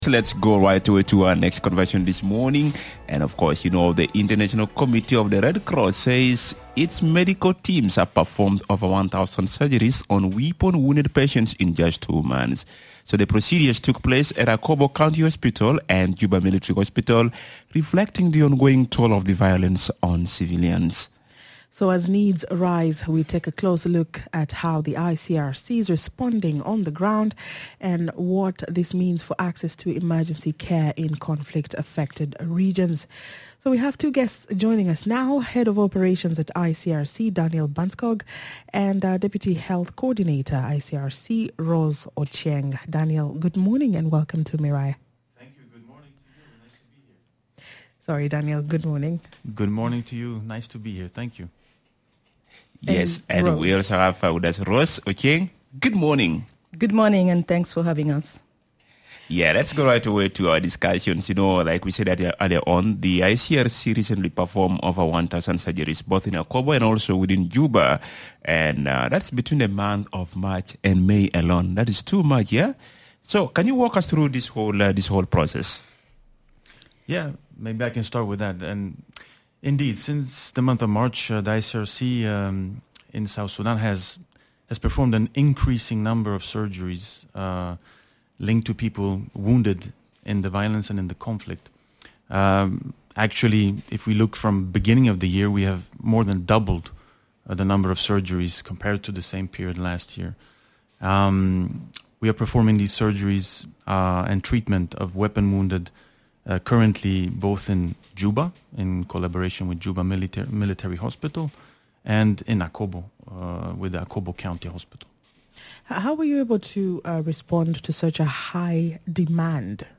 The International Committee of the Red Cross (ICRC) performed over 1,000 surgeries on weapon-wounded patients between March and May 2025 in Akobo and Juba, underscoring the urgent medical needs driven by ongoing violence in South Sudan. In this conversation